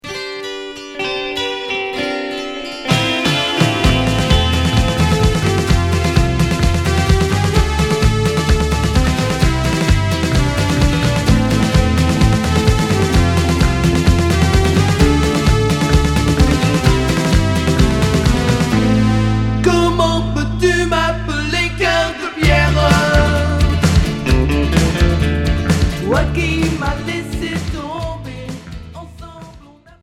Pop rock Deuxième 45t retour à l'accueil